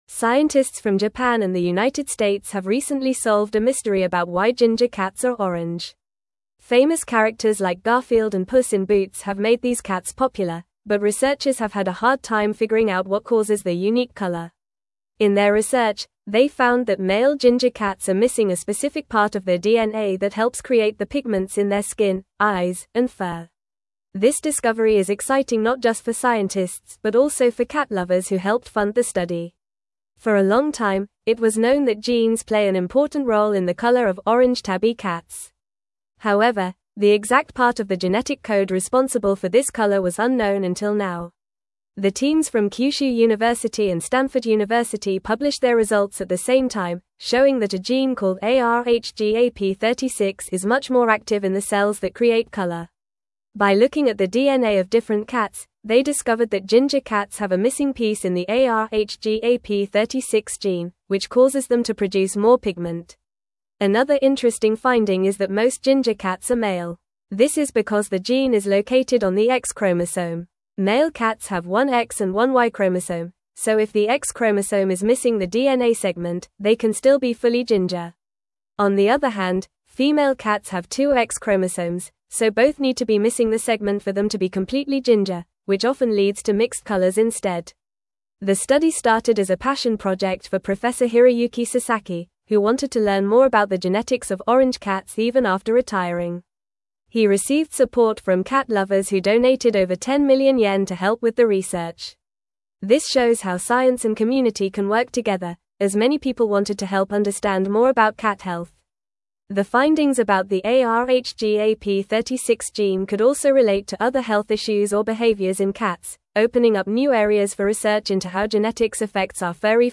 Fast
English-Newsroom-Upper-Intermediate-FAST-Reading-Genetic-Mystery-of-Ginger-Cats-Unveiled-by-Researchers.mp3